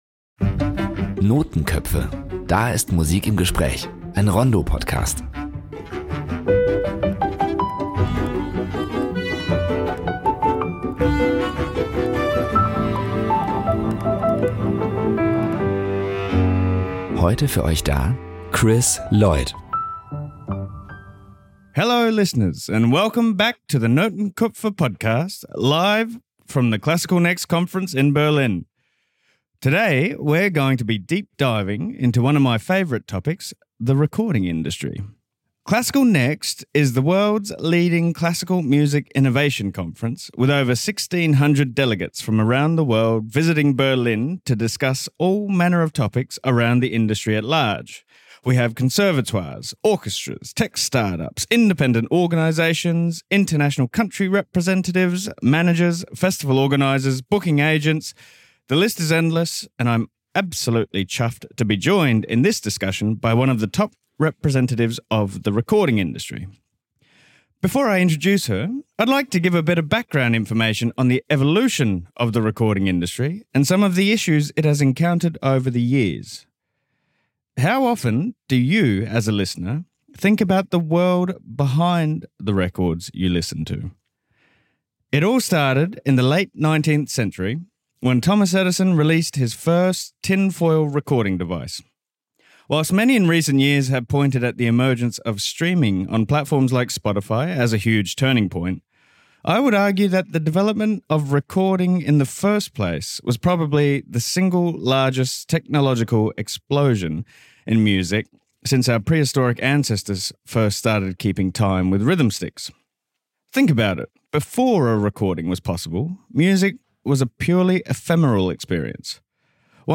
Recorded live at Classical:Next 2025